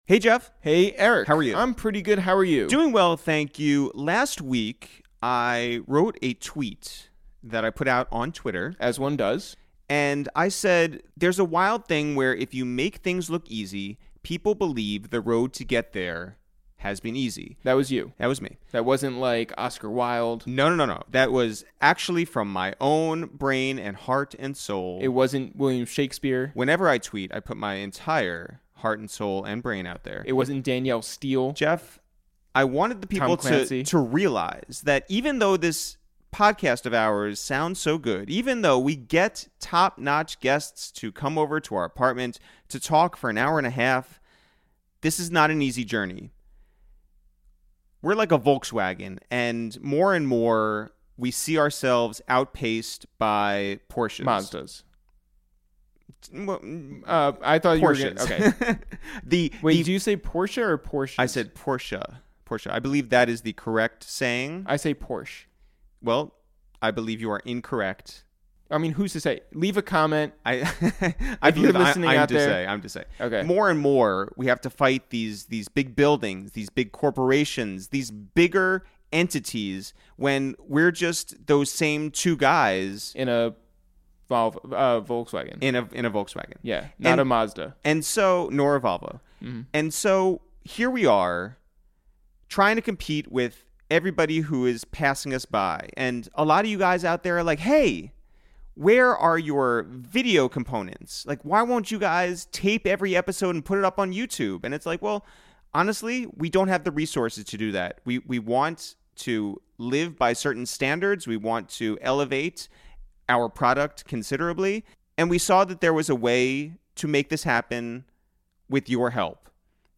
we welcome Toronto's own singer-songwriter Jessie Reyez to the Upper West Side for a wide-ranging, thoughtful and personal conversation.